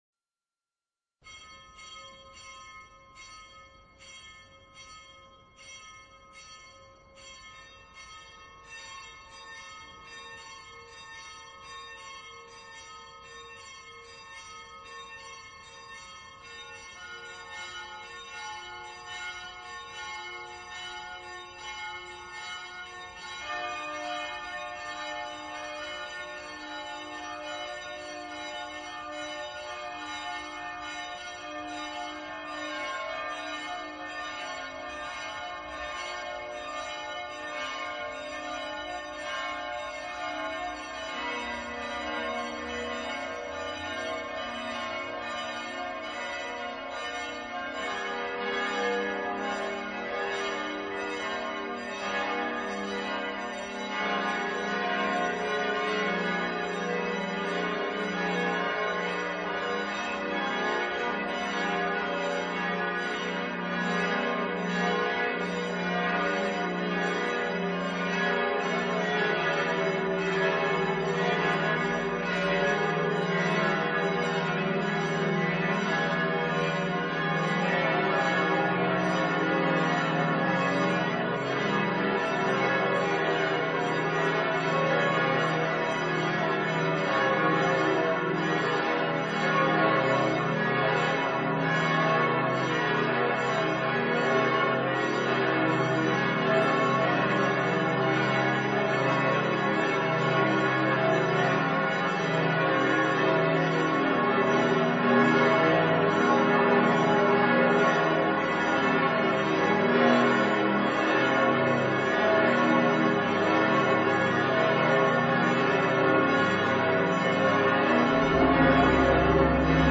Bells are ringing…
A beautiful New Year tradition is the ringing of the church bells.
A beautiful tradition is the ringing of the biggest bell – “Pummerin” (Boomer) at the beginning of the New Year. This bell has a beautiful, deep voice and is only rung on special occasions – like the first moment of the New Year.
Listen to the 12 bells of St. Stephens Cathedral ( very rare occasion to hear all of them)
stephansdom-festgelc3a4ute-pummerin.mp3